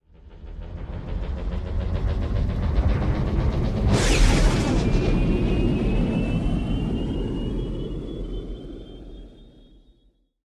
launch3.wav